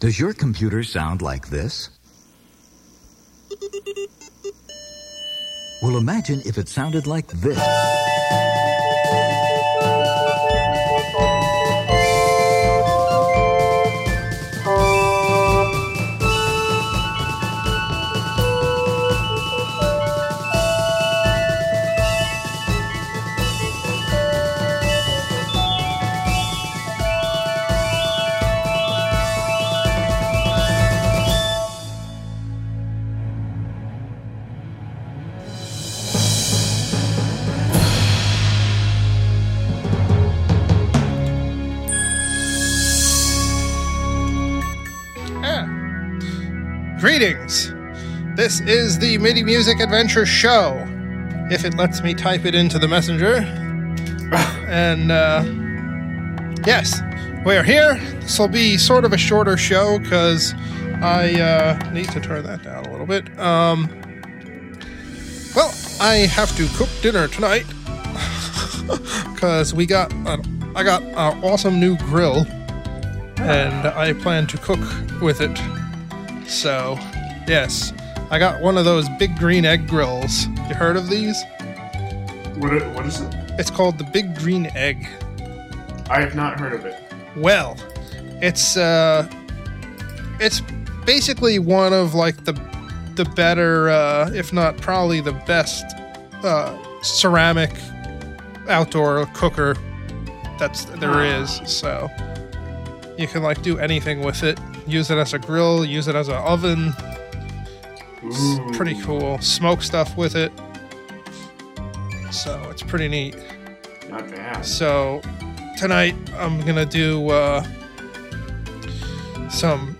In this episode we start playing with some binaural recordings I took with my new Roland R-05 Recorder and CS-10EM Mic’s!